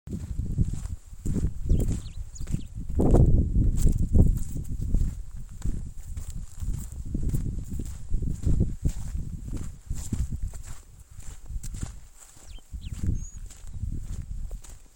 Diuca Finch (Diuca diuca)
Province / Department: Catamarca
Detailed location: Ruta 40- San José
Condition: Wild
Certainty: Photographed, Recorded vocal